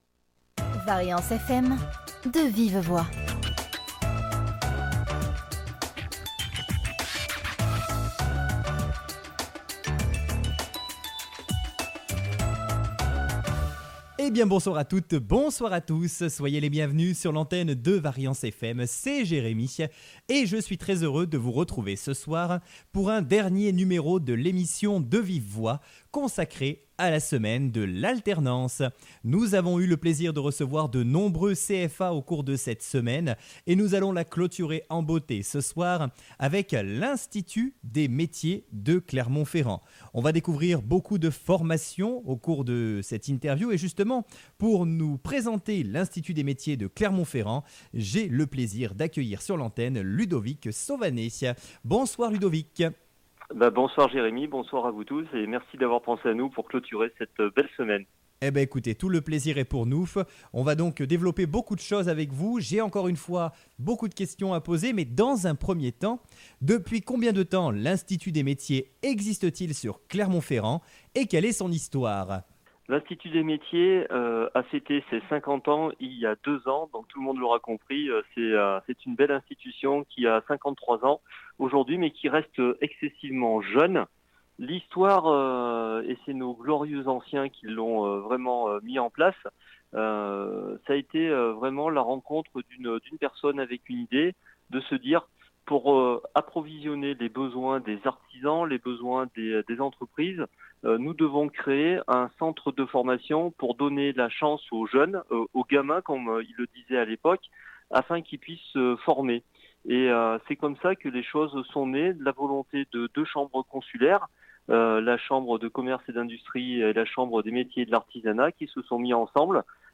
VARIANCE FM – Interview